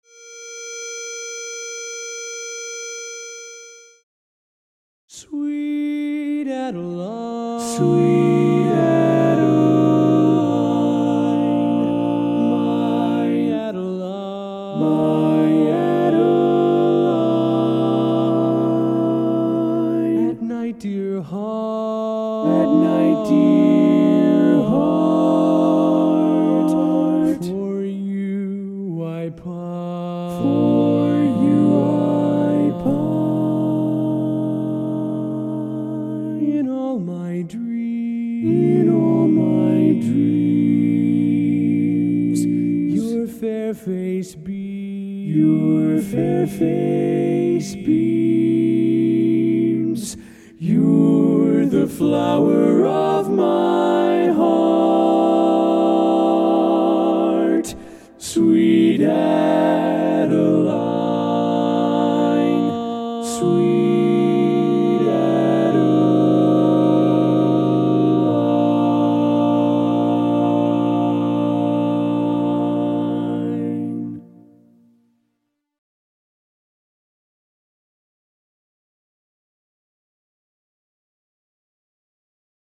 Barbershop
Tenor